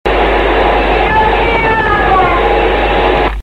Psicofonías  Archivo .Zip  Archivo On Line